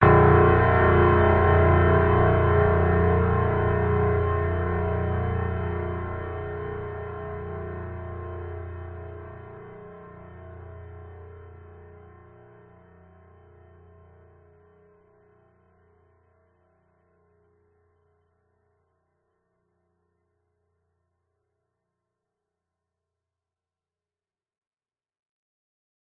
Жуткий удар пианино в низких тонах 2